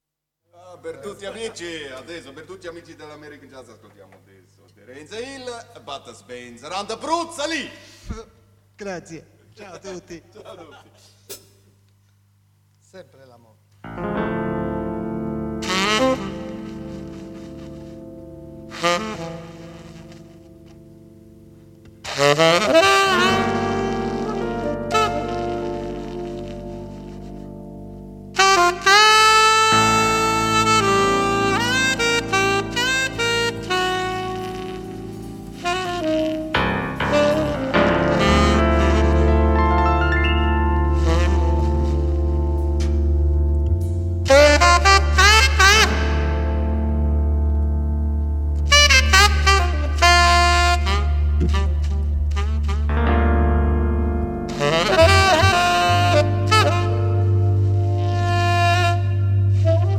Spontaneous Jam Session